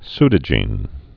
(sdə-jēn)